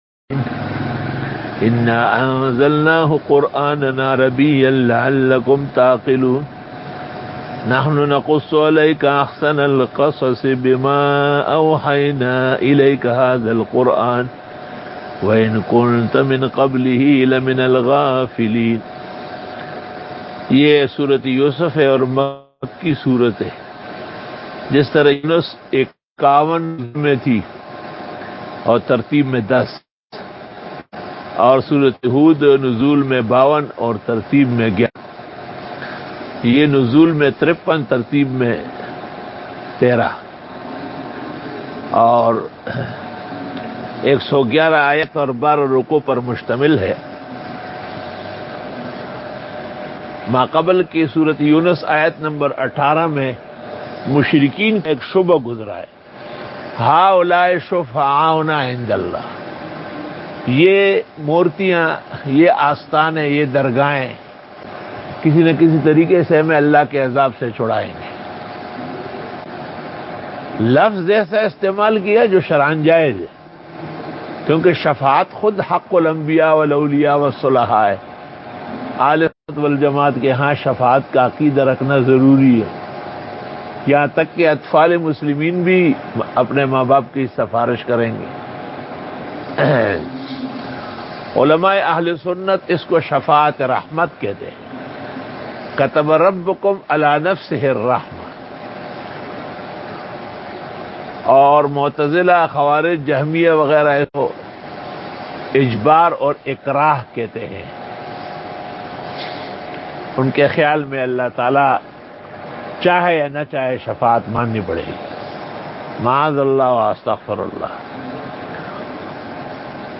دورہ تفسیر القرآن الکریم